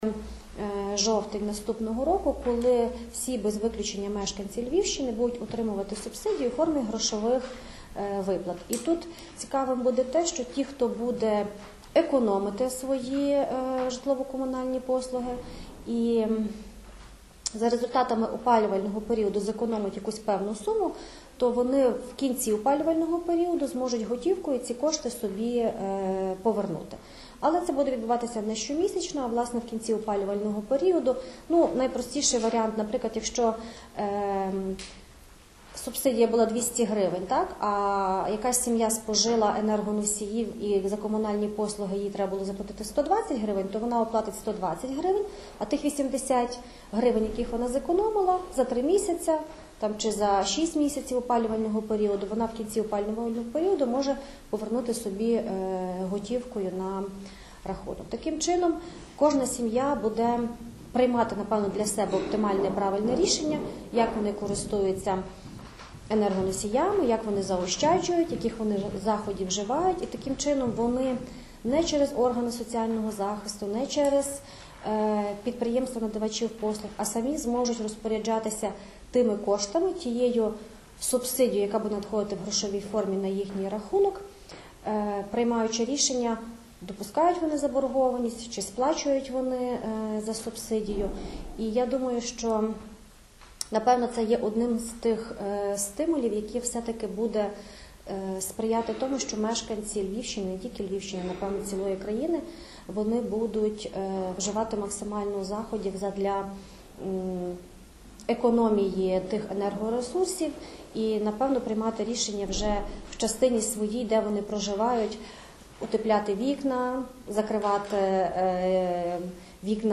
Про це сьогодні, 9 січня, під час брифінгу повідомила директор департаменту соціального захисту населення Львівської обласної державної адміністрації Наталія Кузяк.